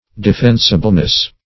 Search Result for " defensibleness" : The Collaborative International Dictionary of English v.0.48: Defensibleness \De*fen"si*ble*ness\, n. Capability of being defended; defensibility.